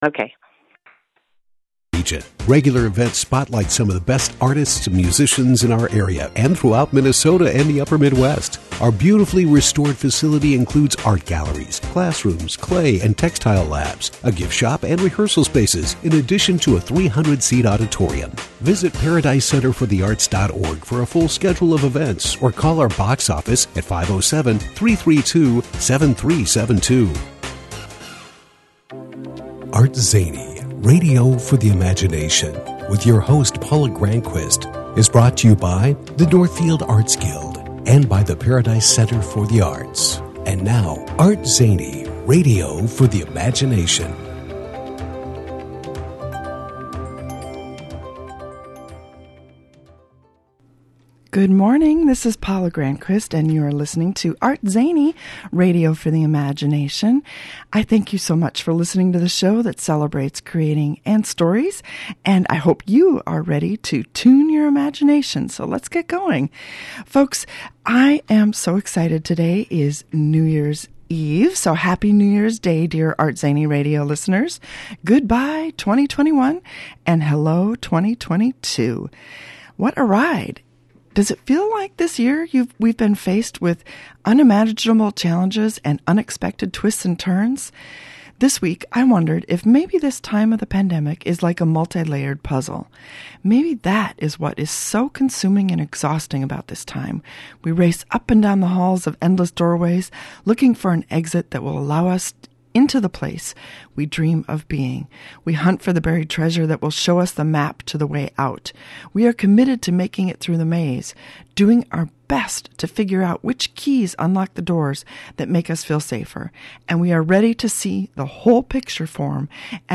Today in the ArtZany Radio studio